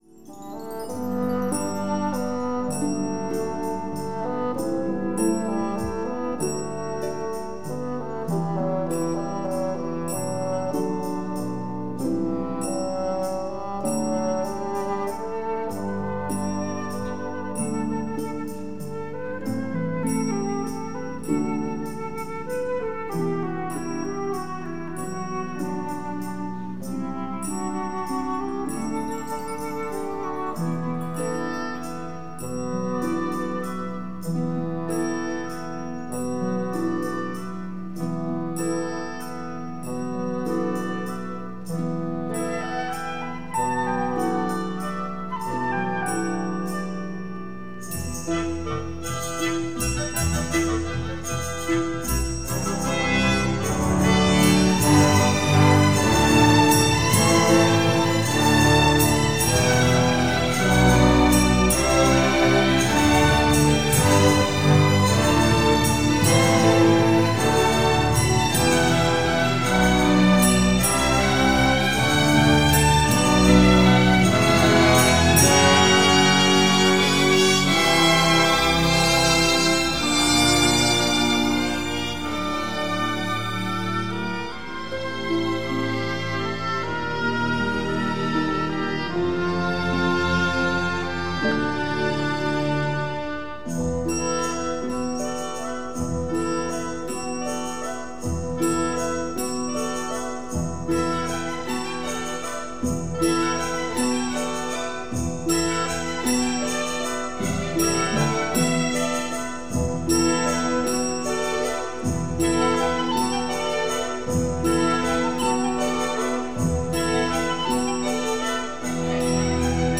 Musica mediterranea di chiaro timbro 'rozsiano'
Di buona qualità.